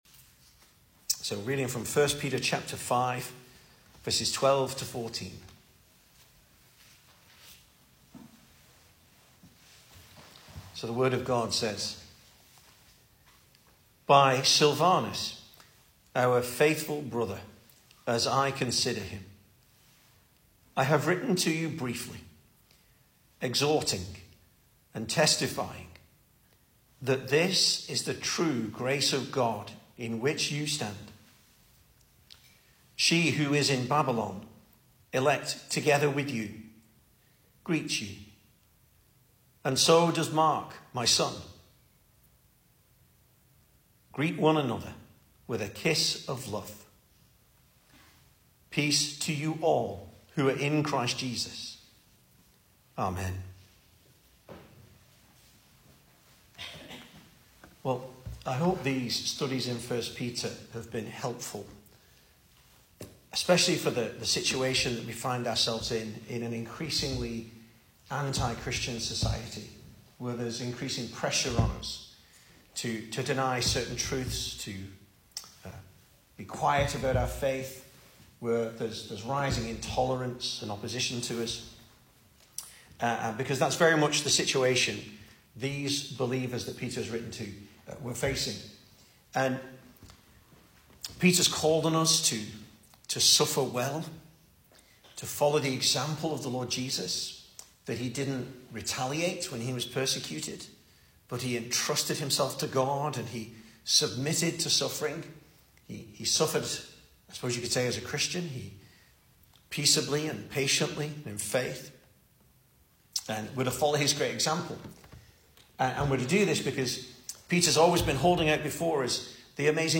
2024 Service Type: Weekday Evening Speaker